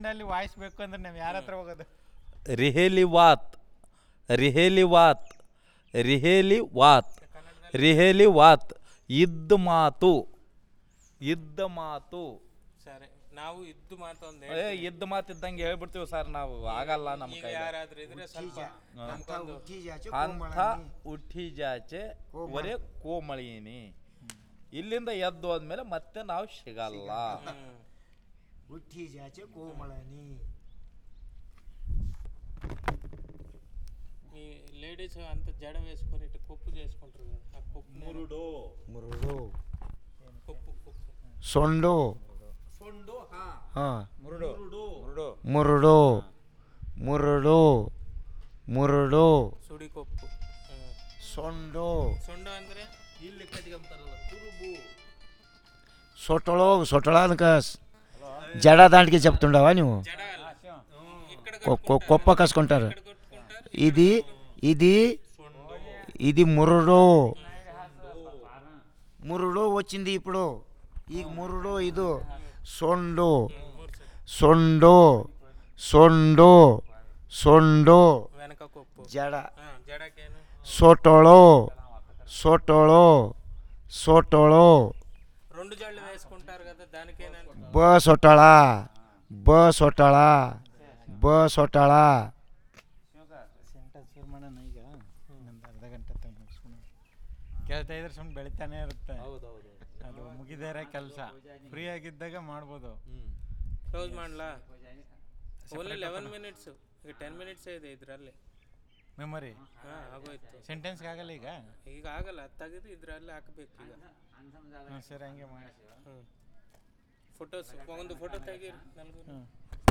NotesThis is an elicitation of words about speech, head knot, marriage by using Language Documentation Handbook.